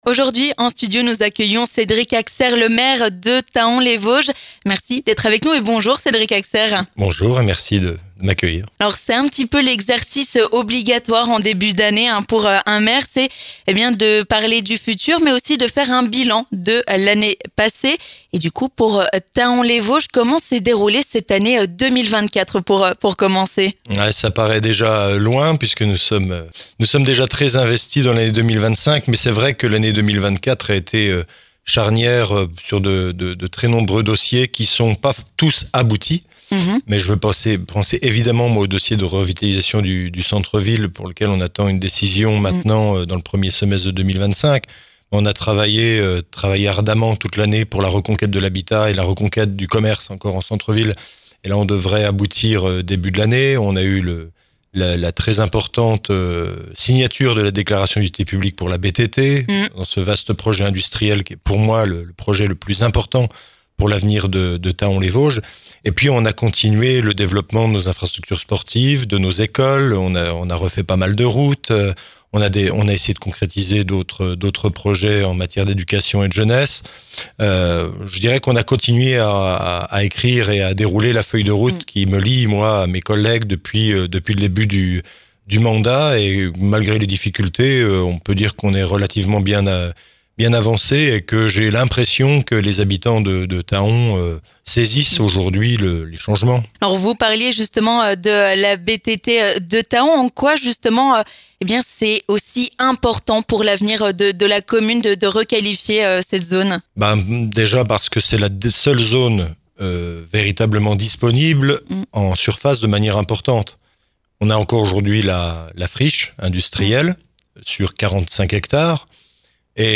Cédric Haxaire, maire de Thaon-les-Vosges, est notre invité sur Vosges FM pour revenir sur les grands projets de l'année 2025 dans sa commune.